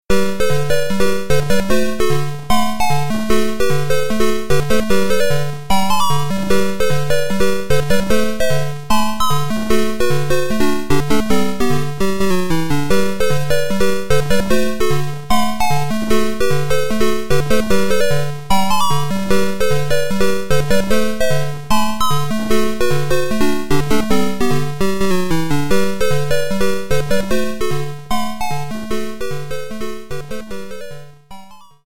irritatingly catchy